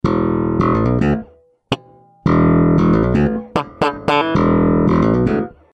Viersaiter mit Select by EMG PUs und passiver Elektronik.
Aufnahmebedingungen: Bass in Boss CS-3 in Sony MD. Klangregelung neutral!
Slap (Brücken PU)
Spirit-XT2-Slap.mp3